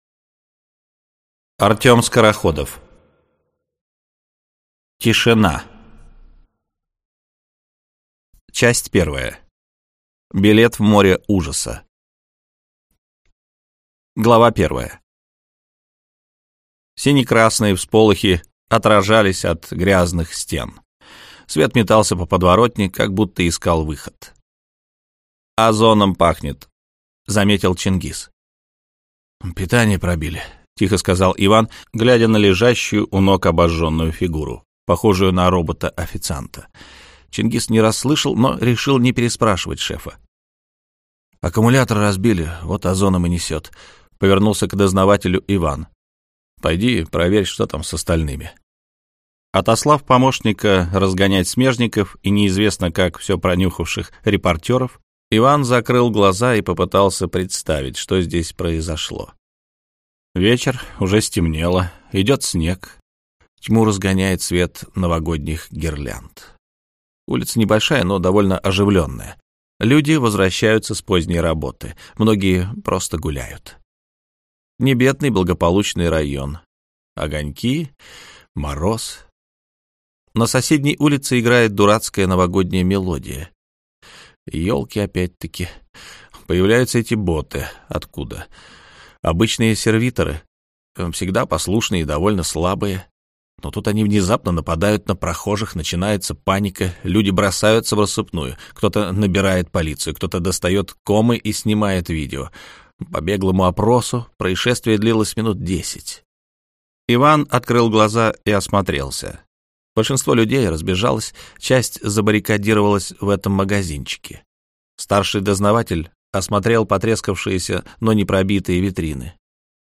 Аудиокнига Тишина | Библиотека аудиокниг